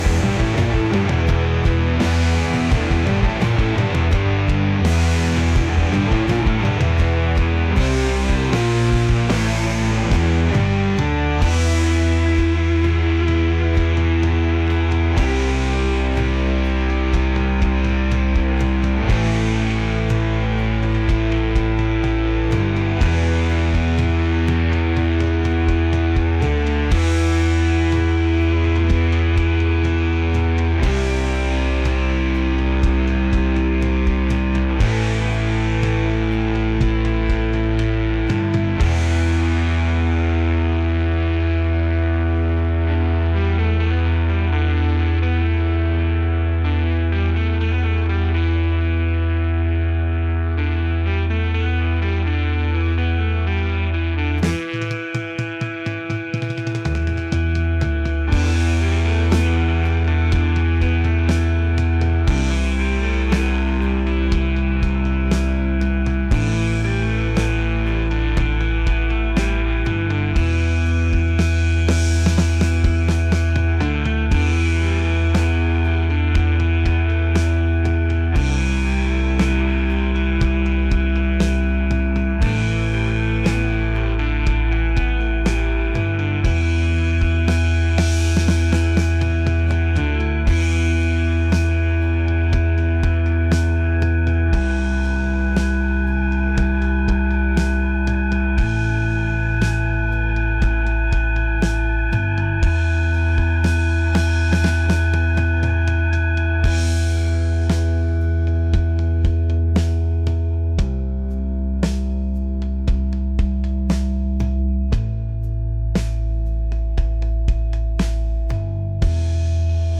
heavy